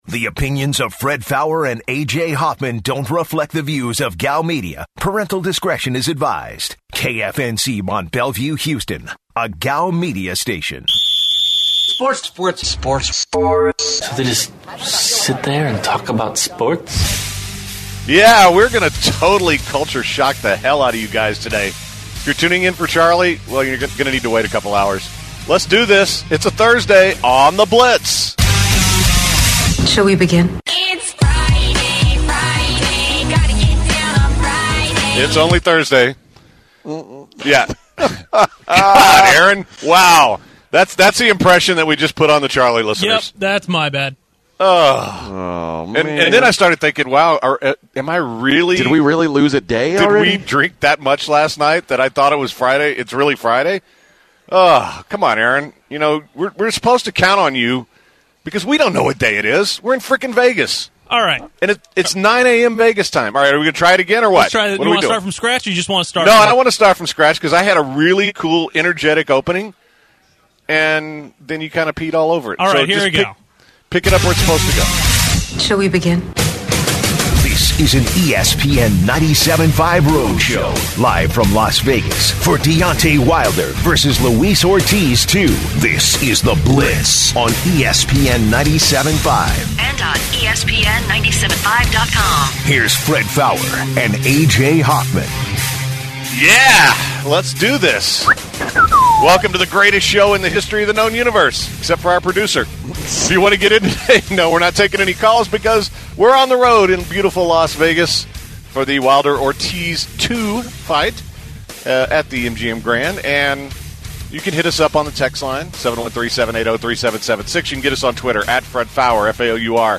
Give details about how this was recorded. broadcast live from Las Vegas for Wilder vs. Ortiz II during a special time slot!